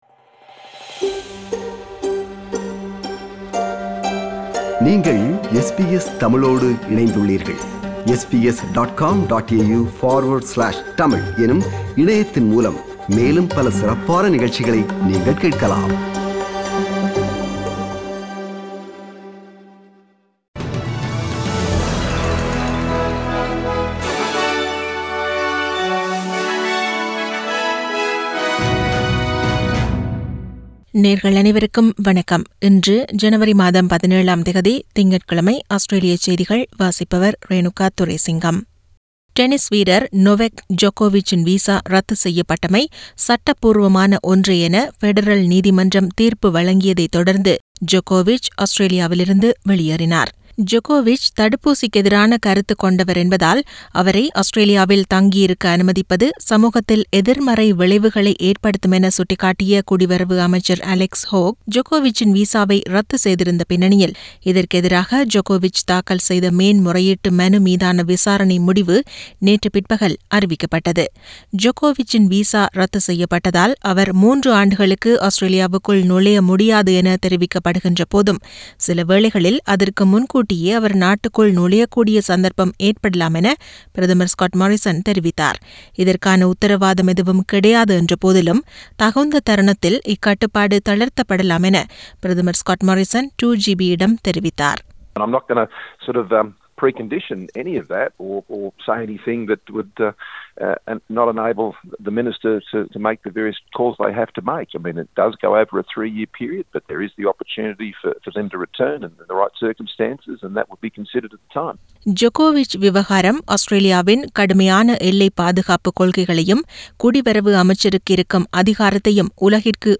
Australian news bulletin for Monday 17 Jan 2022.